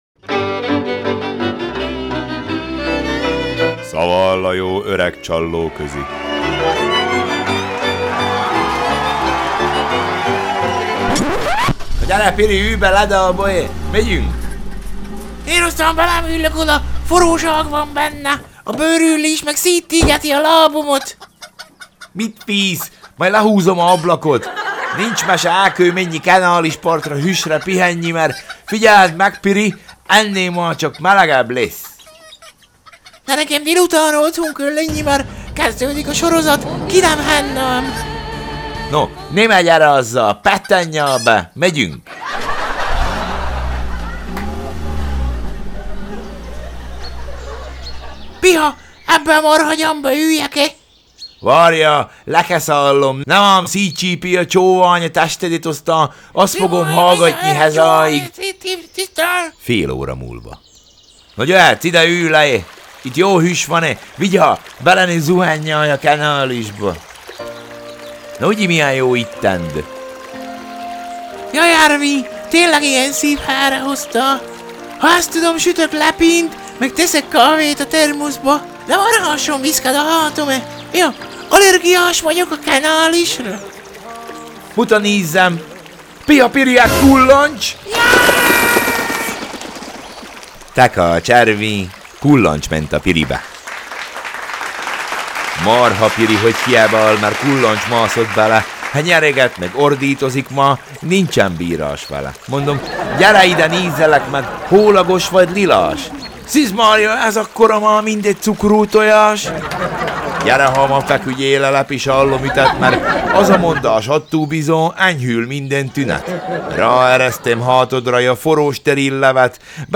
Szaval a jó öreg csallóközi